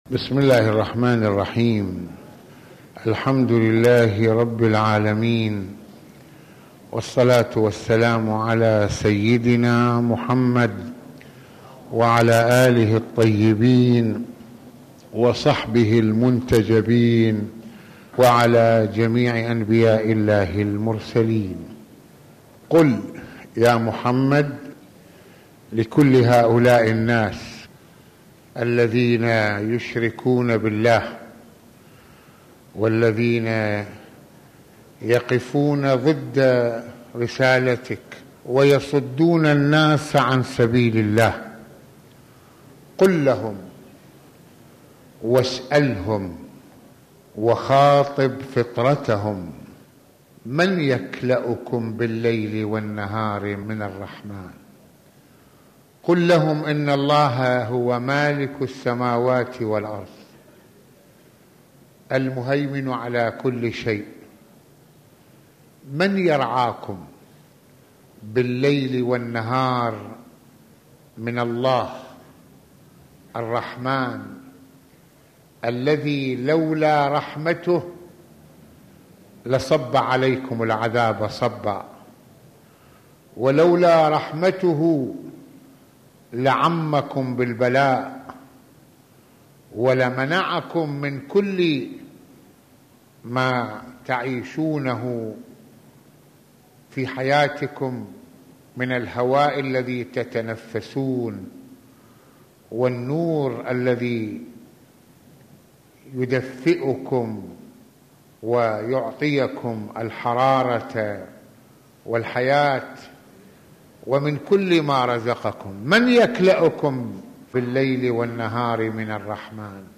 - يتعرض المرجع السيد فضل الله (رض) في هذه المحاضرة القرآنية للآيات 42 إلى 47 من سورة الأنبياء المباركة وهنا الكلام حول رحمة الله لعباده وما ينبغي أن ينفتحوا على حمد ربهم بتوحيده بدل الإعراض عنه والذي يؤدي إلى خسارة الدنيا والآخرة كما حصل مع المشركين ويتناول سماحته مسألة موازين الله الدقيقة والعادلة في حساب الناس وضرروة الإعداد الصالح لهذا اليوم من أجل الفوز بمرضاة الله تعالى...